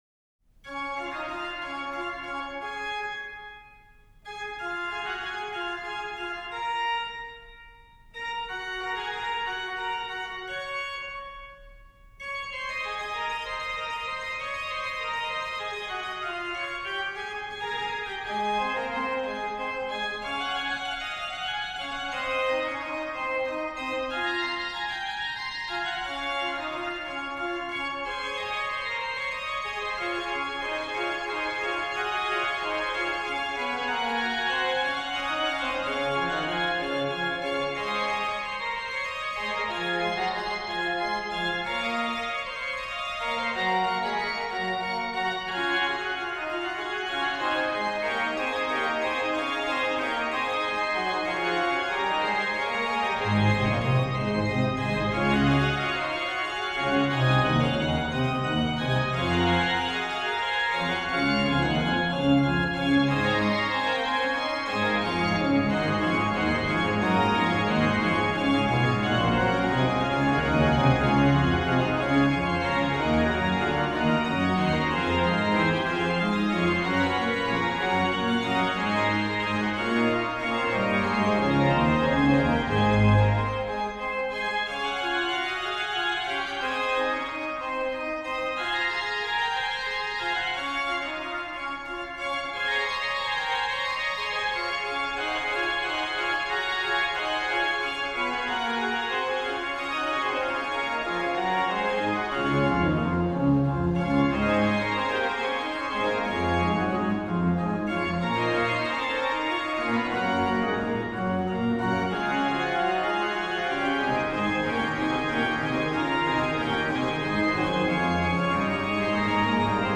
BW: Ged8, Nacht8, Oct4, Gms4, Oct2, Mix